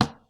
Impact
Wood 4.wav